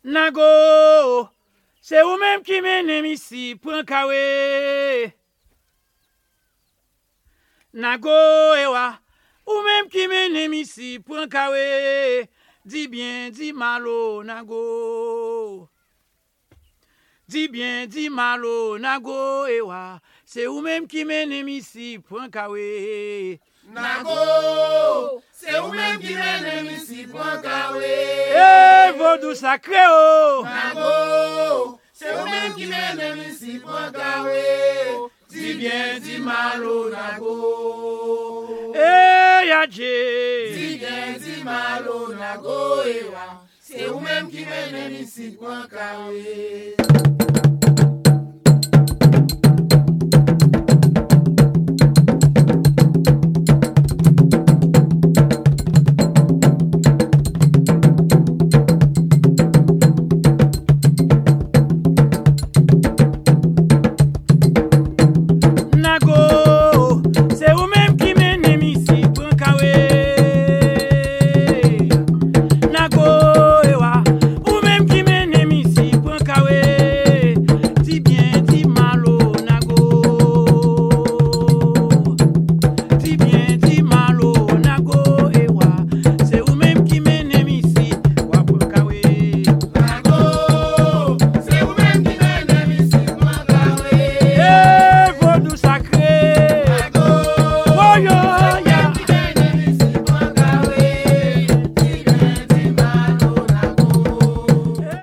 太鼓とパーカッション、歌とコーラスで繰り広げられるハイチのヴードゥー・アンサンブル！